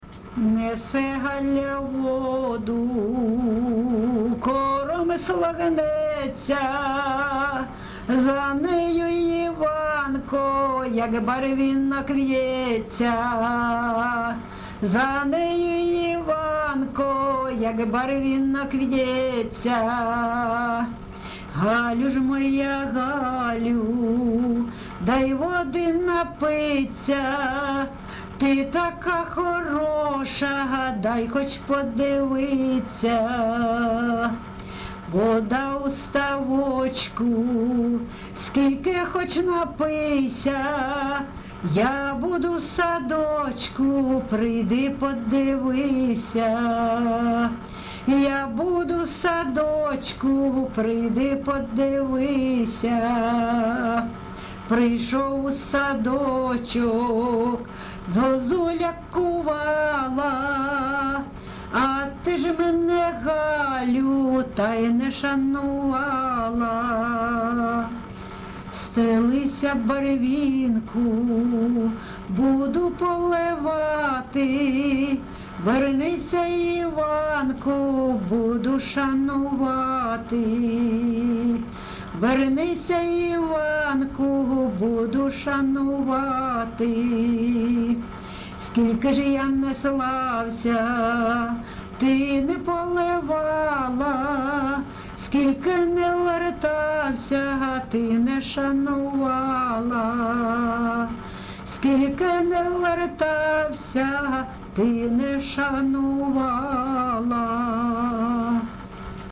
ЖанрПісні з особистого та родинного життя, Сучасні пісні та новотвори
Місце записус. Лозовівка, Старобільський район, Луганська обл., Україна, Слобожанщина